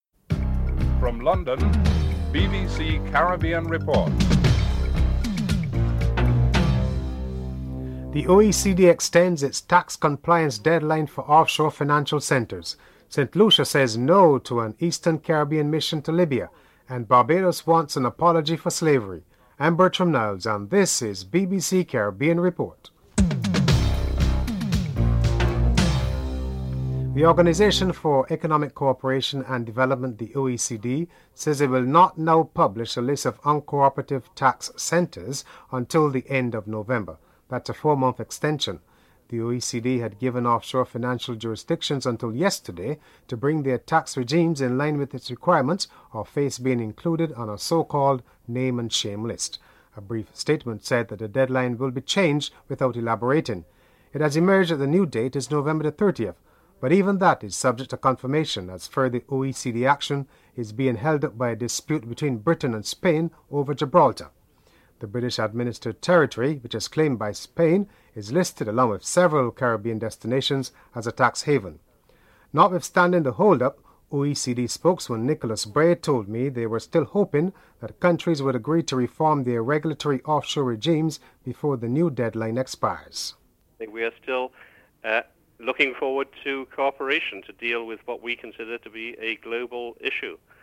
1. Headlines (00:00-00:27)
5. Barbados Minister of Education and Culture Mia Mottley is heading her country's preparations for the United Nations Conference. Minister Mia Mottley is interviewed (10:17-13:33)